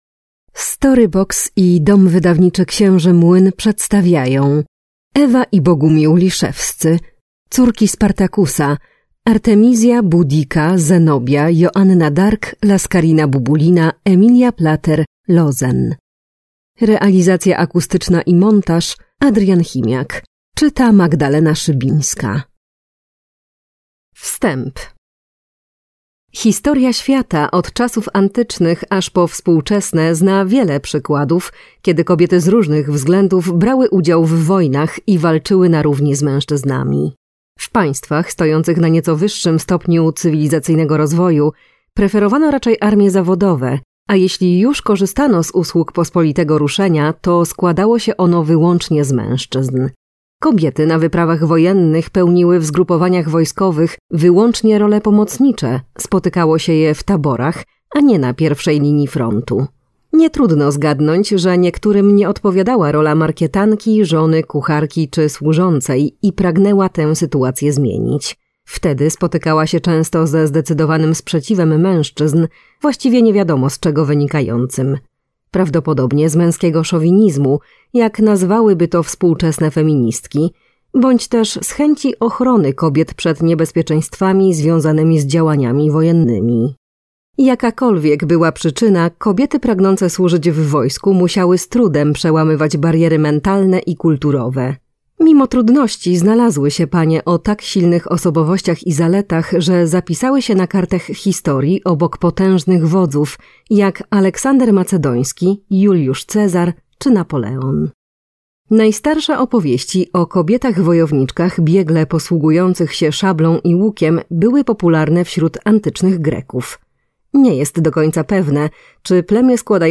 Córki Spartakusa - Ewa Liszewska, Bogumił Liszewski - audiobook